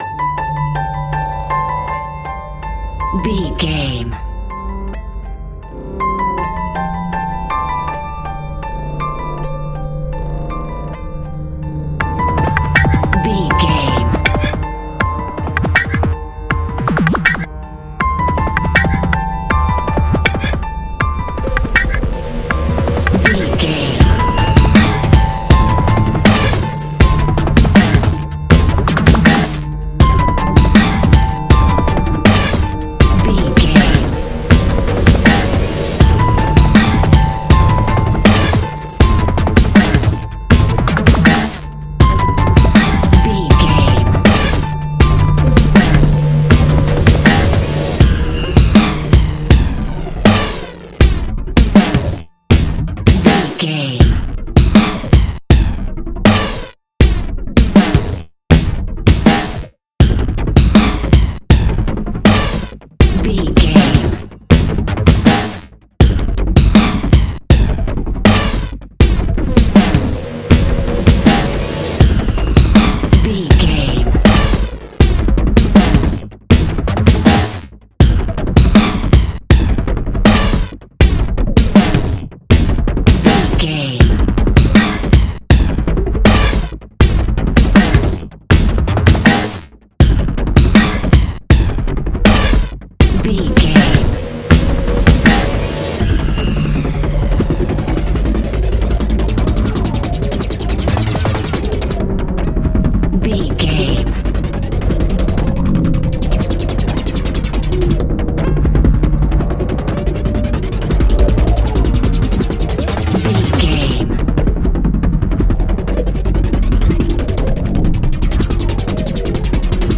Thriller
In-crescendo
Aeolian/Minor
Fast
aggressive
dark
hypnotic
industrial
heavy
drum machine
synthesiser
piano
breakbeat
energetic
synth leads
synth bass